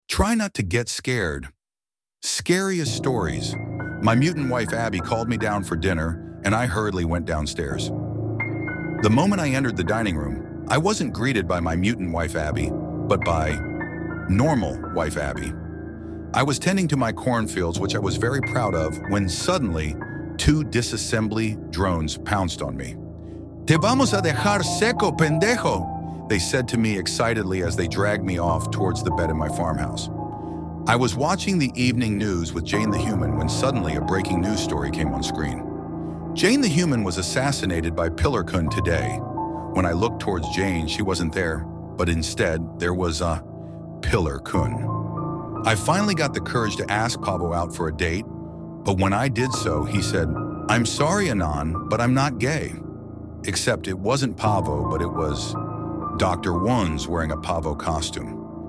Spooky
ElevenLabs